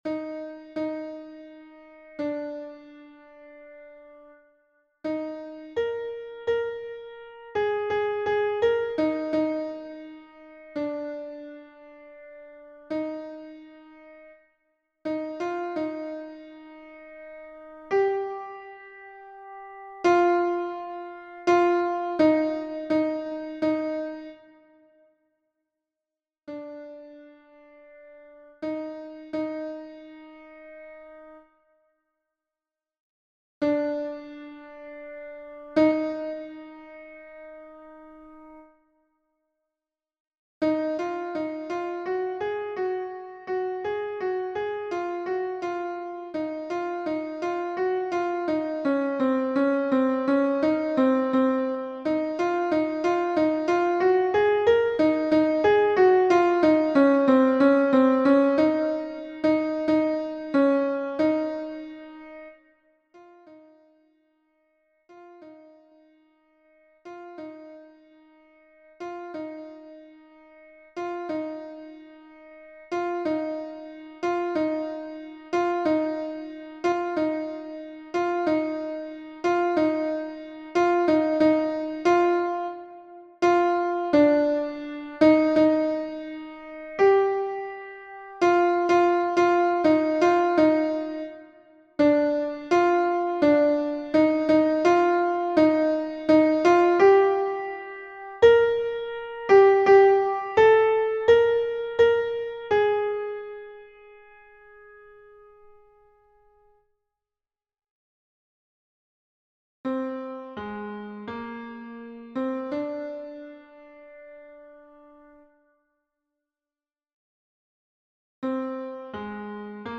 MP3 version piano
Alto 2